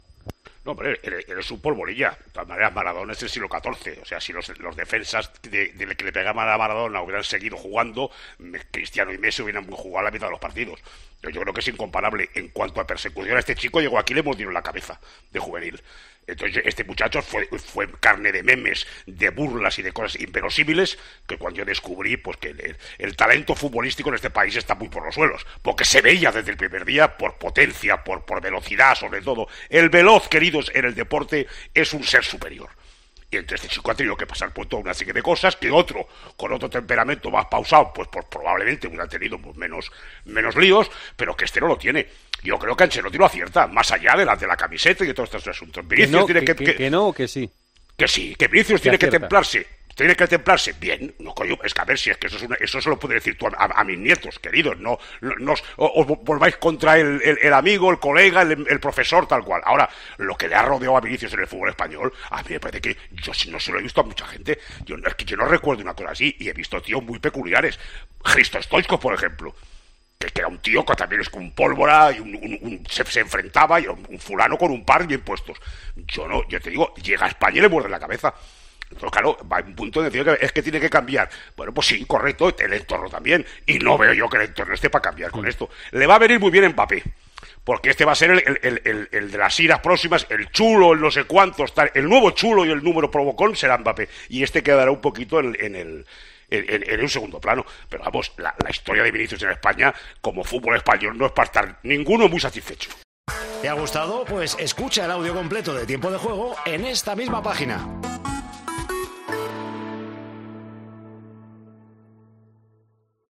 El tertuliano y comentarista de Tiempo de Juego valora el papel que tendrá el futbolista francés en el vestuario del equipo blanco.